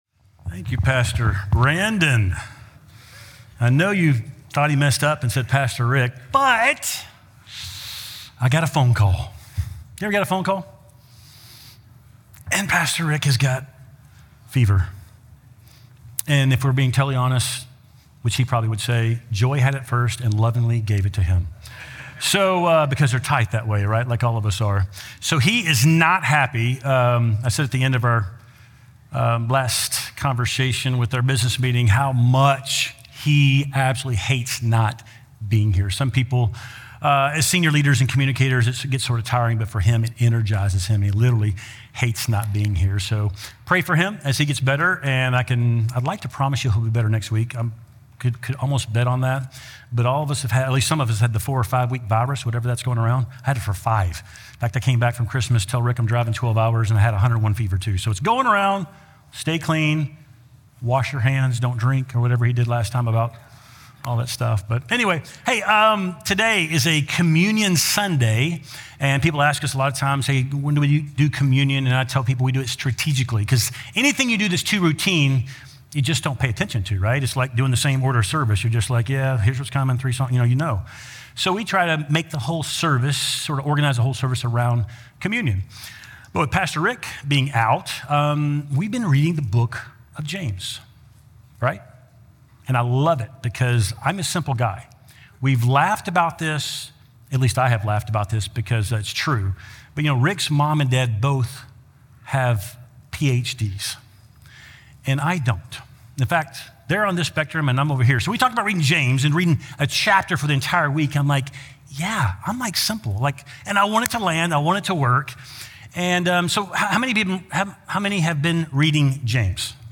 Capitol City Church Podcast (Sermon Audio) (Sermon Audio)